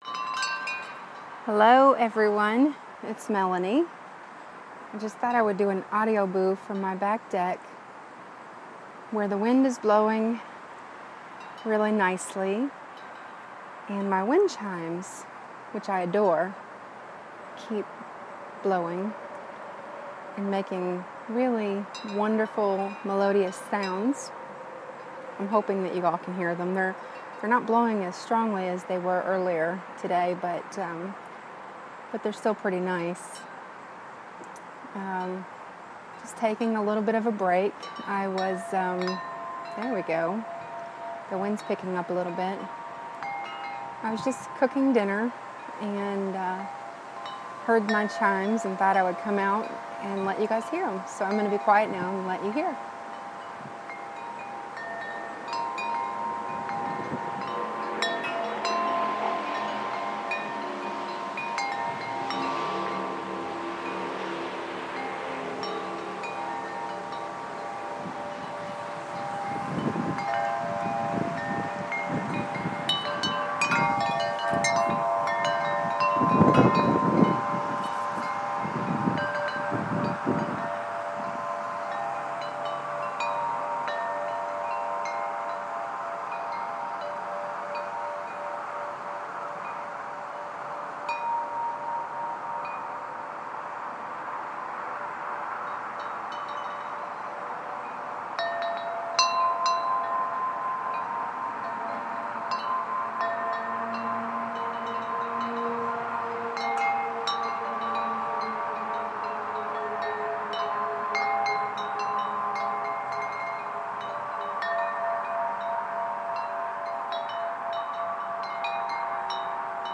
Wind chimes